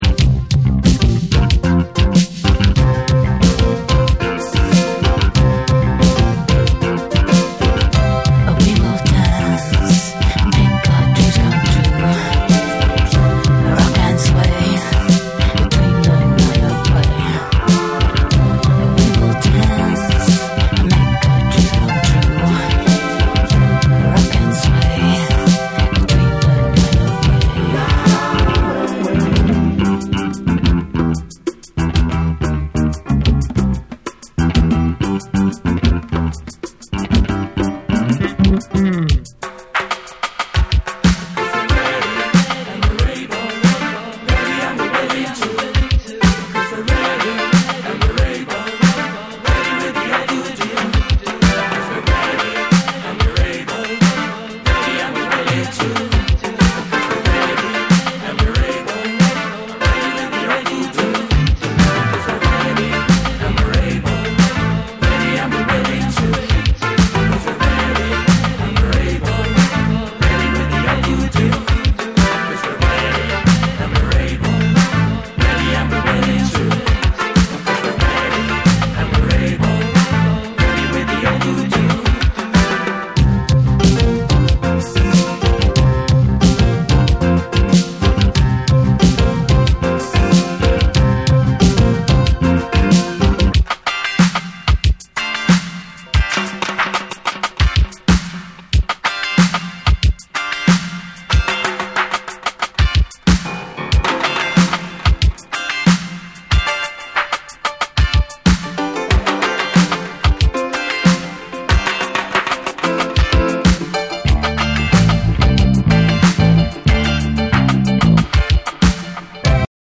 NEW WAVE & ROCK / REGGAE & DUB